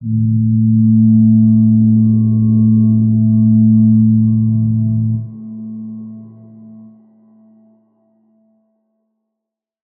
G_Crystal-A3-pp.wav